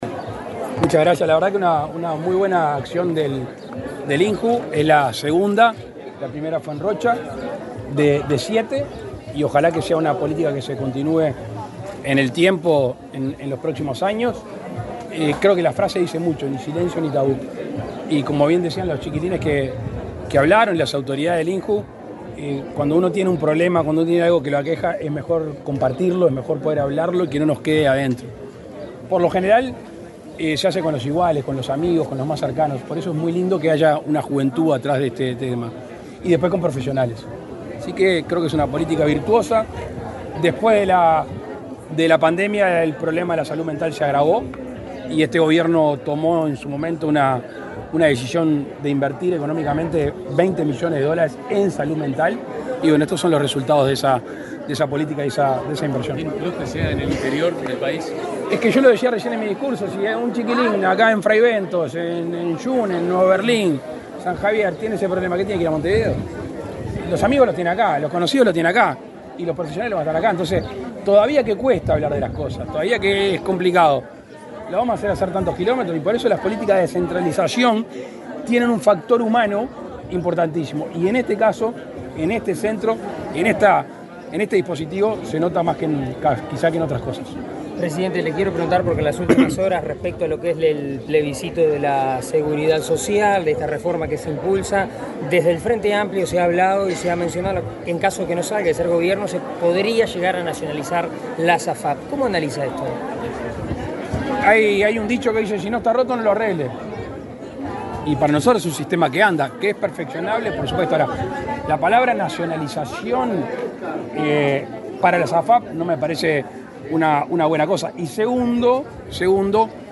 Declaraciones del presidente Lacalle Pou a la prensa
Declaraciones del presidente Lacalle Pou a la prensa 22/10/2024 Compartir Facebook X Copiar enlace WhatsApp LinkedIn El presidente de la República, Luis Lacalle Pou, participó en Fray Bentos, departamento de Río Negro, en la presentación de las instalaciones del centro Ni Silencio Ni Tabú. Luego, dialogó con la prensa.